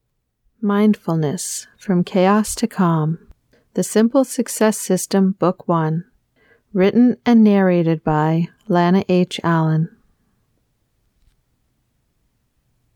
mindfulness audiobook